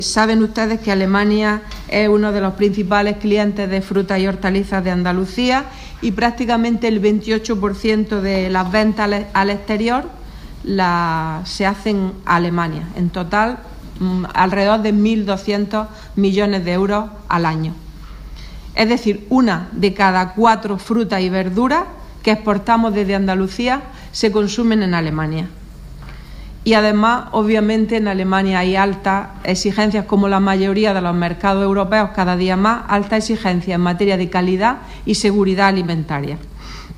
Declaraciones de Carmen Ortiz sobre exportaciones andaluzas a Alemania